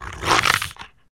gorilla-sound